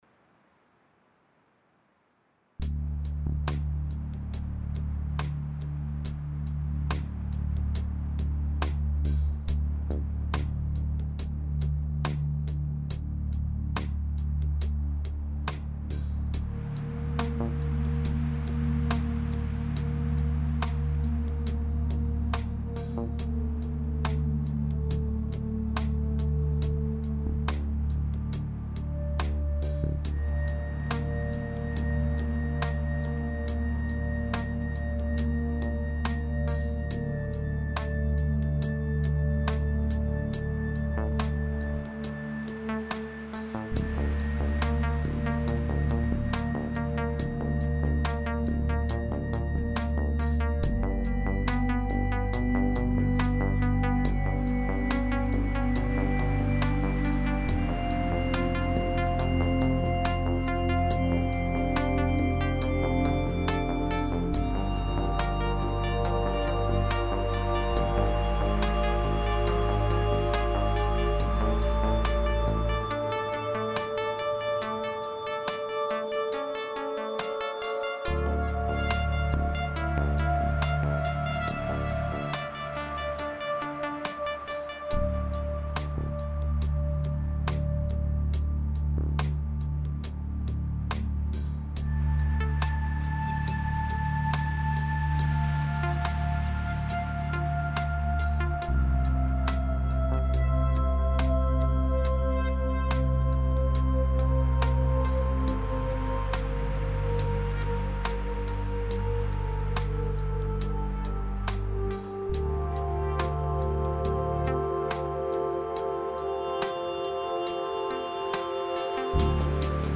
Yamaha synthesizers Modx/Modx+/Montage